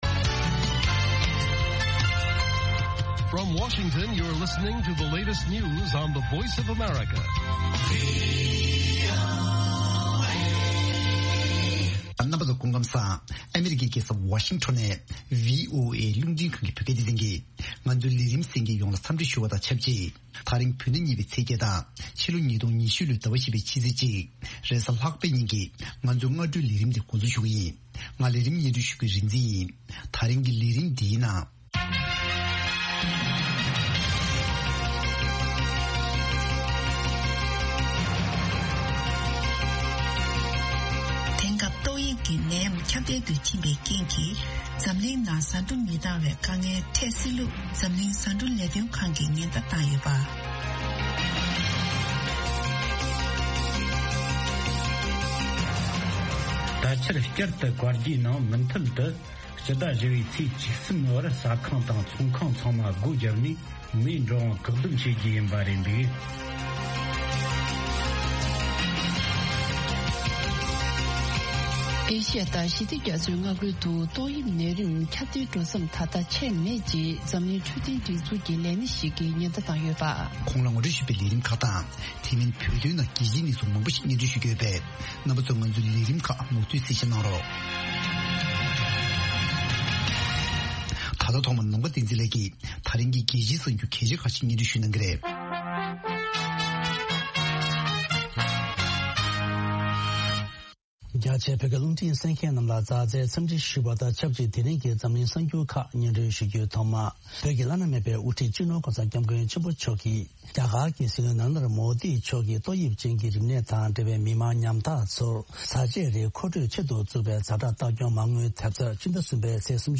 Afternoon Show Broadcast daily at 12:00 Noon Tibet time, the Lunchtime Show presents a regional and world news update, followed by a compilation of the best correspondent reports and feature stories from the last two shows. An excellent program for catching up on the latest news and hearing reports and programs you may have missed in the morning or the previous night.